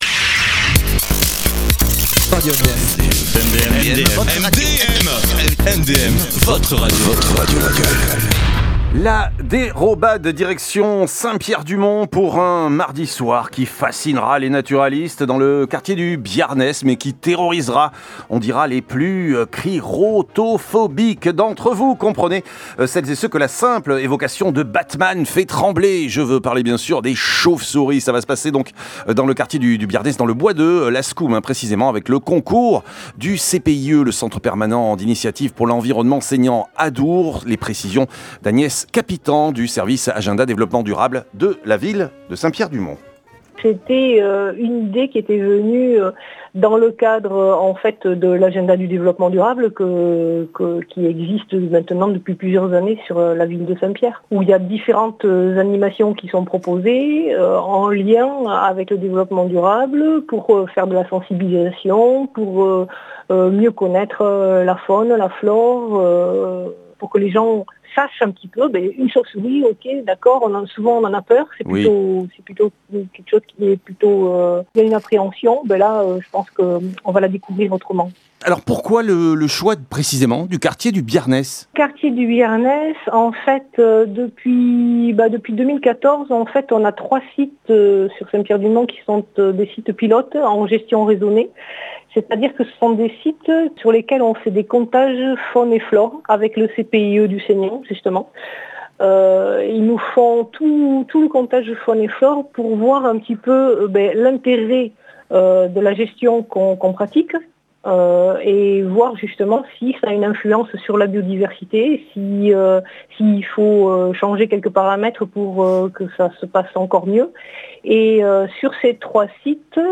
Une rencontre nocturne dans le bois de Lascoumes, initiée par la Ville de Saint Pierre du Mont avec le concours du CPIE ( Centre permanent d’initiatives pour l’environnement) Seignanx A…